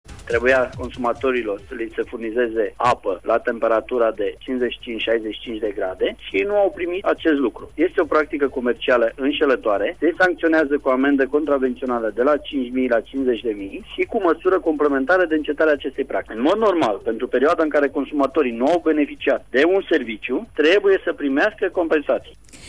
Presedintele ANPC, Marius Dunca, spune ca in acest caz a fost incalcata o clauza contractuala.